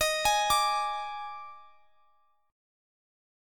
D#M7sus4 Chord
Listen to D#M7sus4 strummed